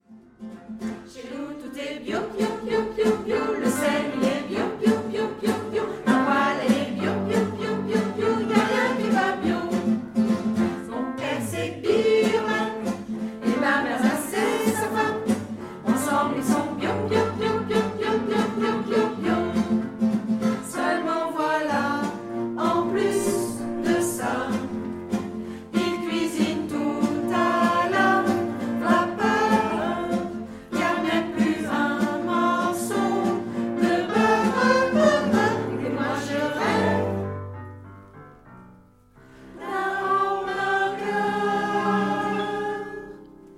Un caractère très enjoué, de l’humour et de l’ironie…